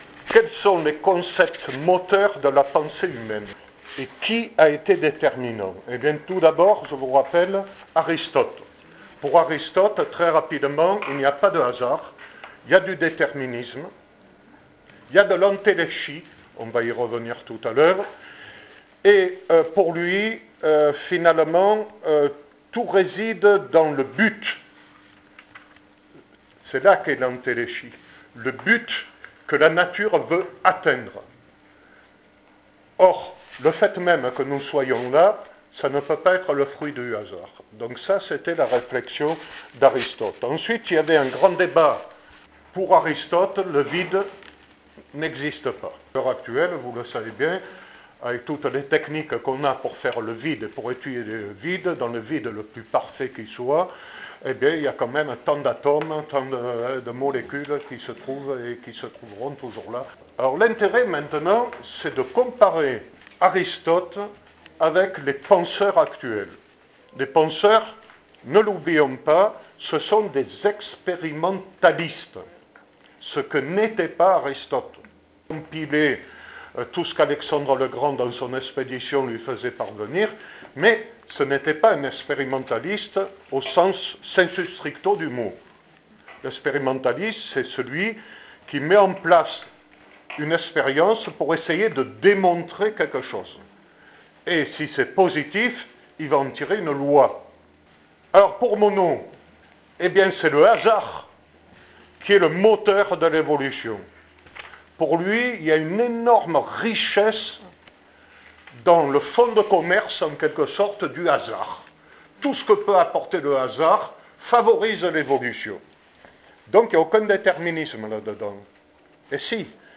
Conférence enregistrée à l'Université du temps libre d'Avignon (janvier 2014)